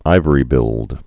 (īvə-rē-bĭld, īvrē-)